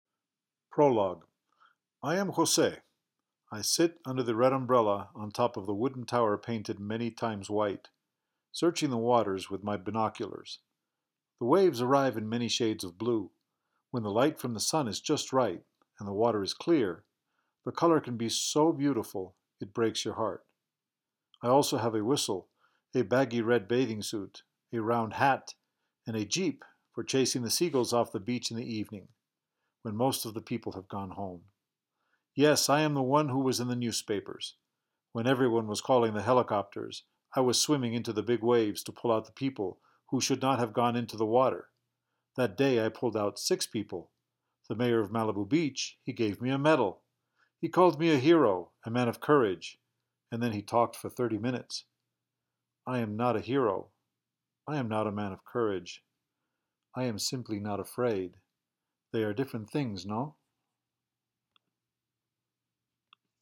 American accent